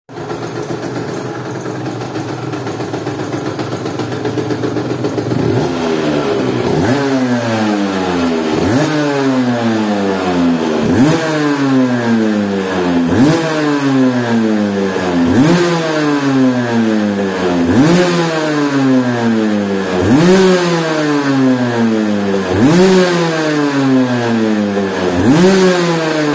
sound Aprilia RS 125 stock sound effects free download